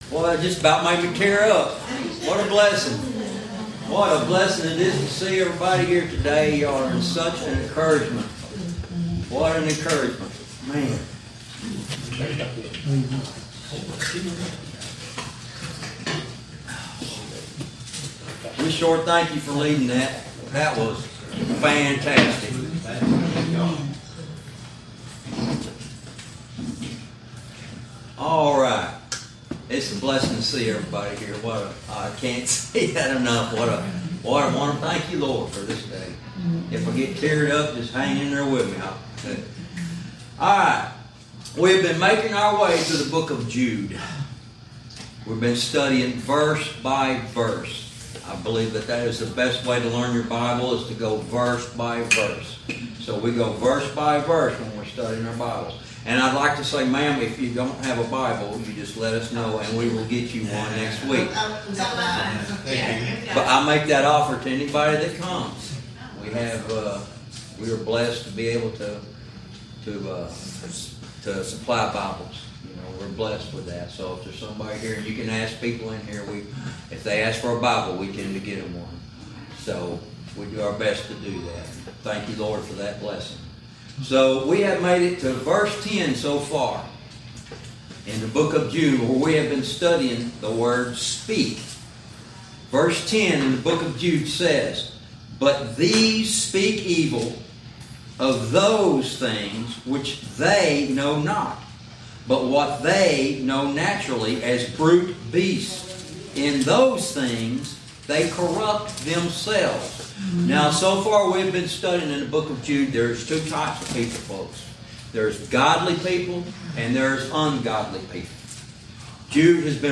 Verse by verse teaching - Lesson 36